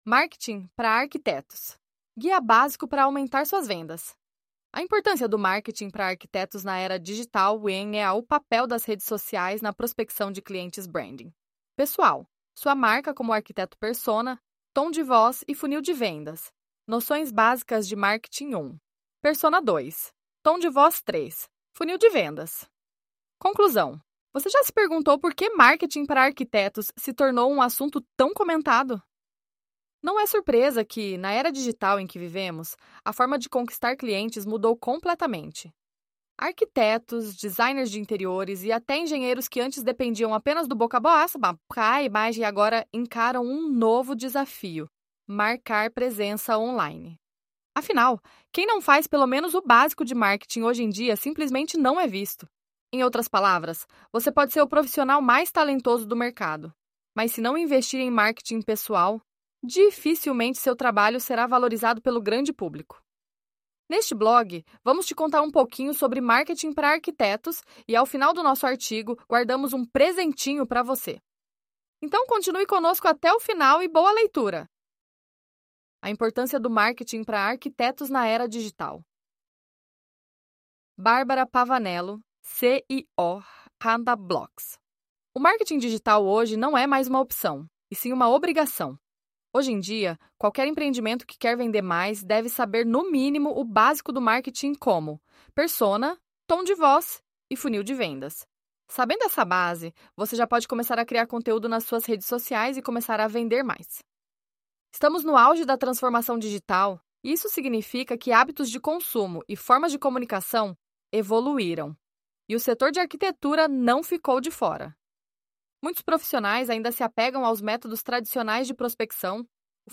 🎧 Sem tempo para ler? Então, dê um play e escute nosso artigo!
ElevenLabs_Untitled_project-1.mp3